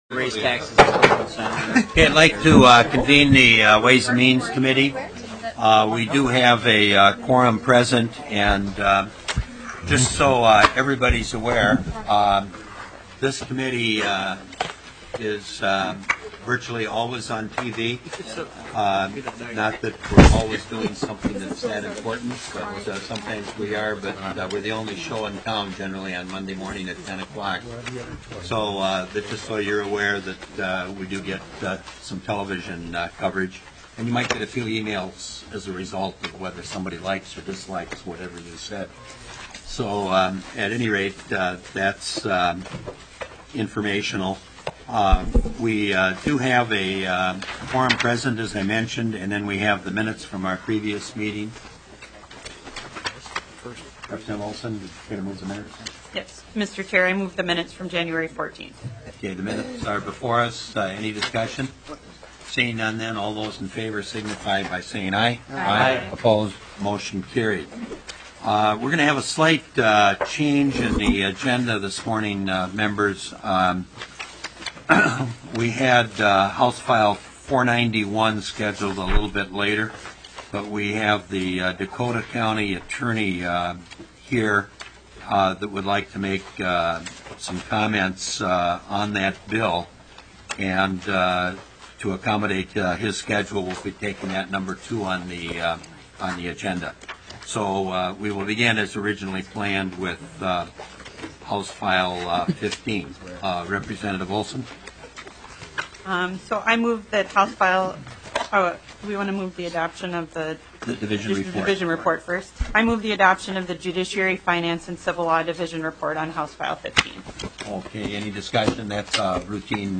Ways and Means SECOND MEETING - Minnesota House of Representatives